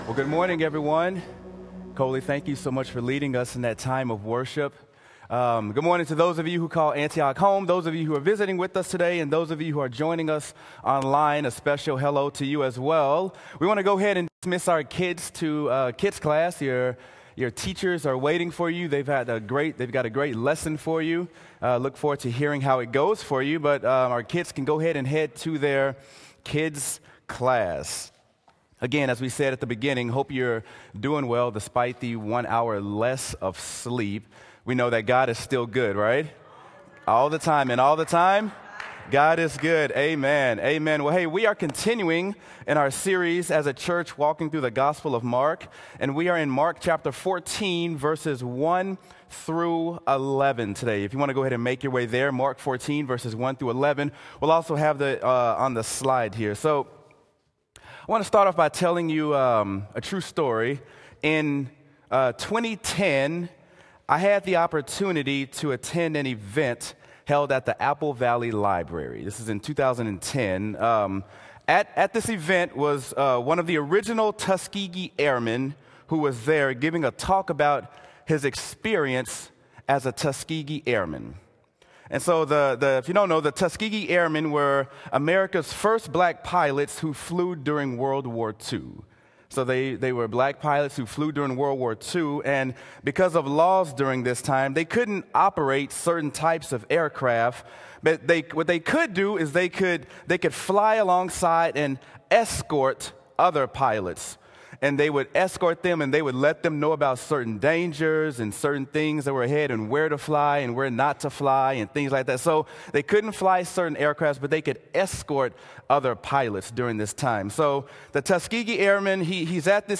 Sermon: Mark: A Beautiful Thing | Antioch Community Church - Minneapolis
sermon-mark-a-beautiful-thing.m4a